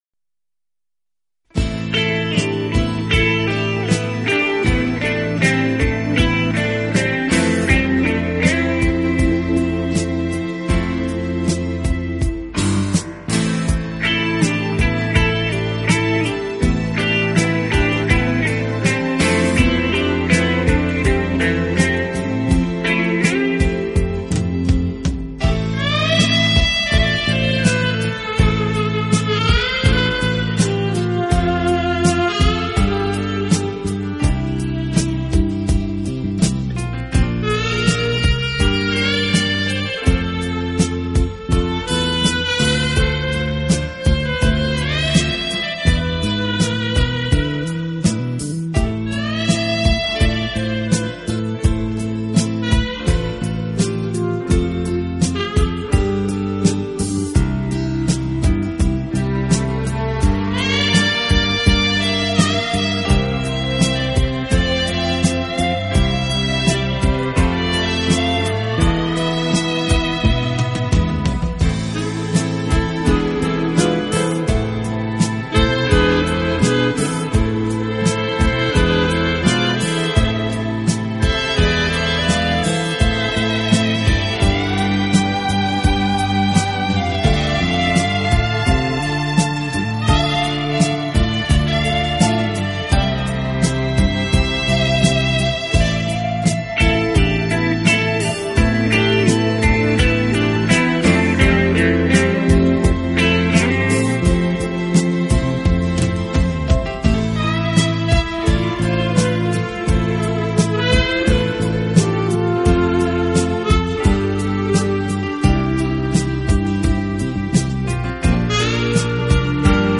【浪漫萨克斯】